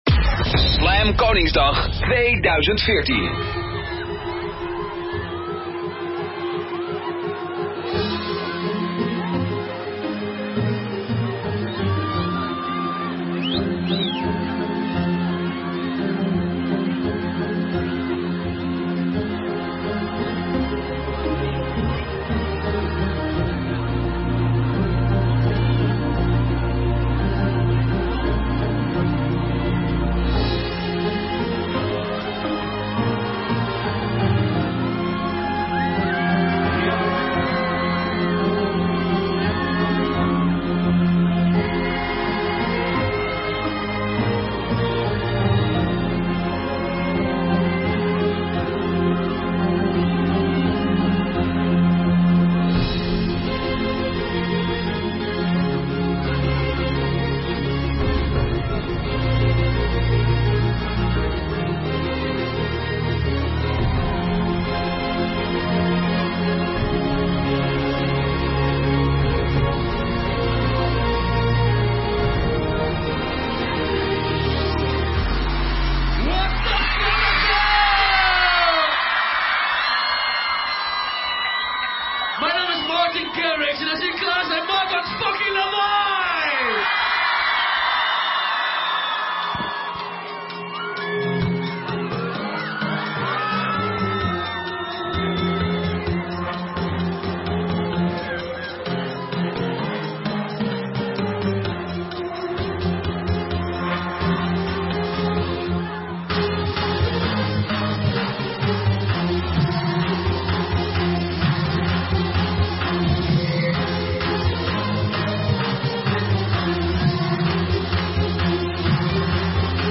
Nhạc Latin, Nhạc Mỹ Latinh